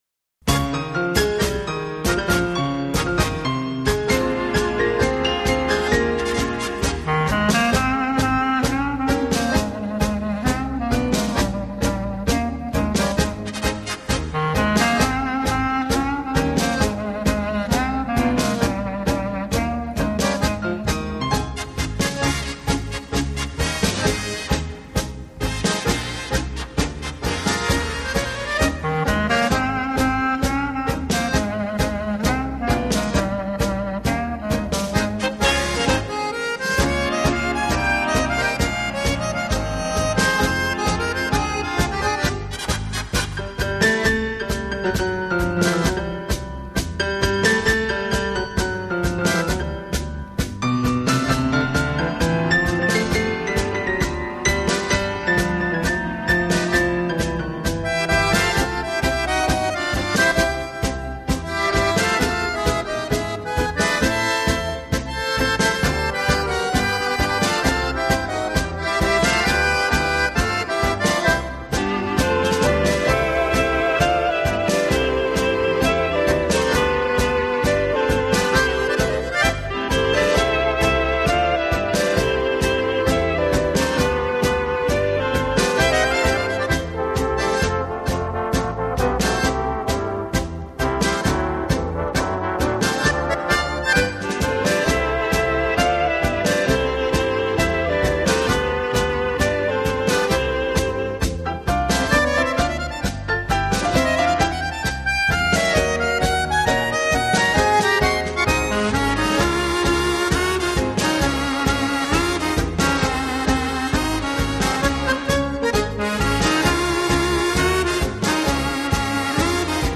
他的乐队以演奏舞